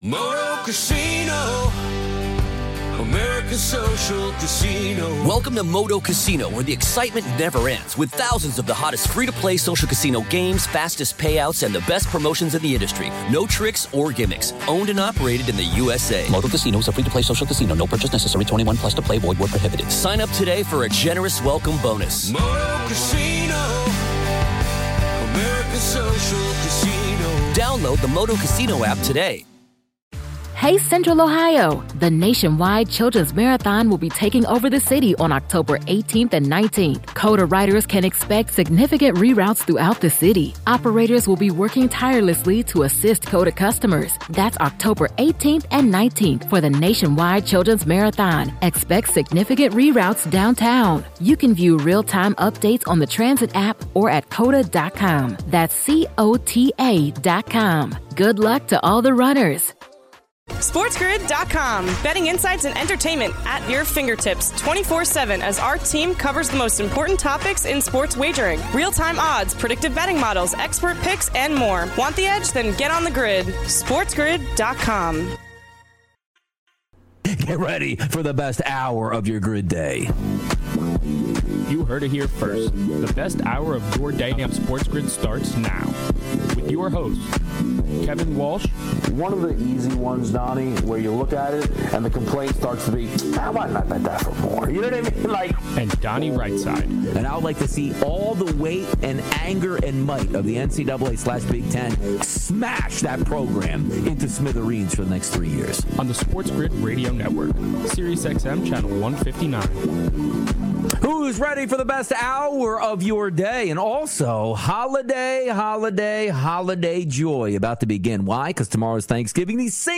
All this, your calls, his bets, and more!